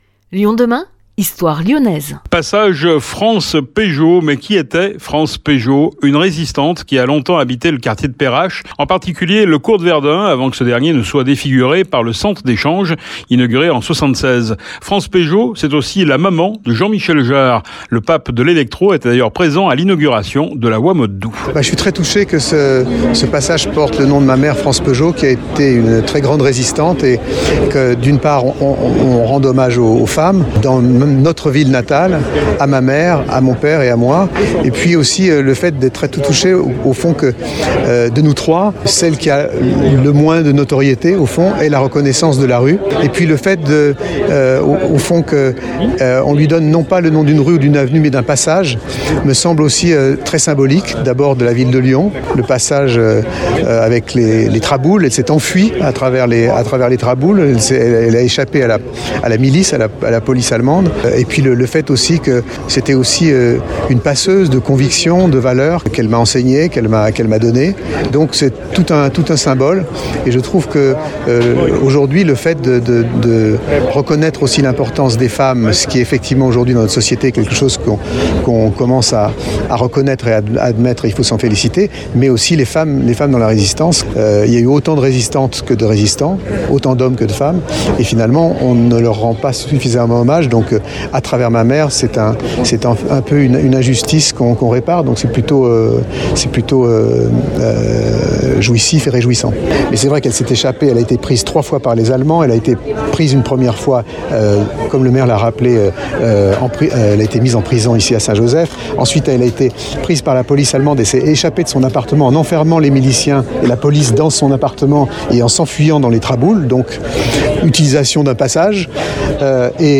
Le pape de l’électro était d’ailleurs présent à l’inauguration de la voie modes doux (Passage France Pejot)